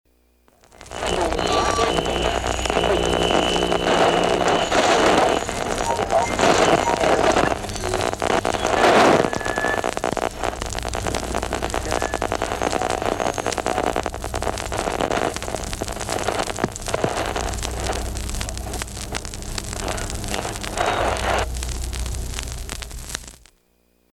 SFX – RADIO STATIC
SFX-RADIO-STATIC.mp3